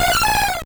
Cri de Floravol dans Pokémon Or et Argent.